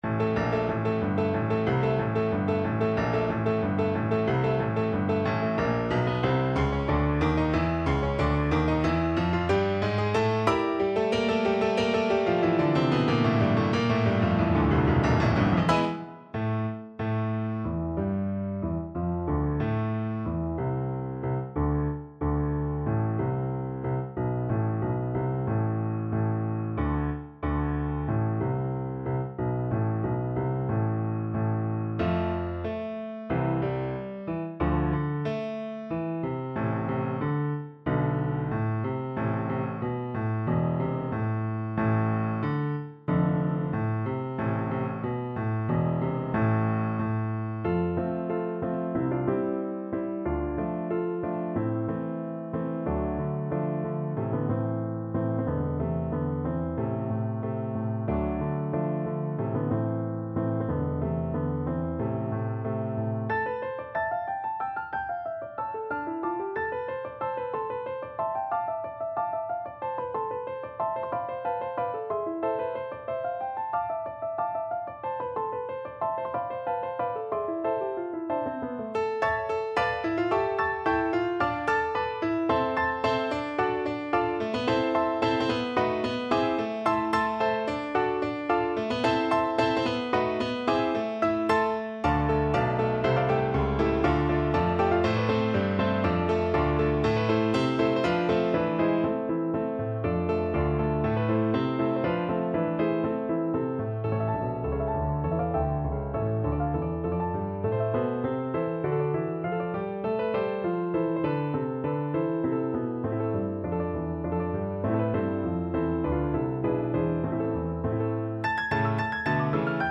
No parts available for this pieces as it is for solo piano.
Allegro =92 (View more music marked Allegro)
2/4 (View more 2/4 Music)
Piano  (View more Advanced Piano Music)
Classical (View more Classical Piano Music)